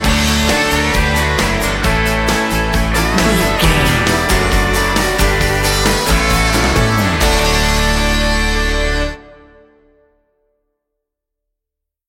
Ionian/Major
D
electric guitar
drums
bass guitar
violin
Pop Country
country rock
bluegrass
happy
uplifting
driving
high energy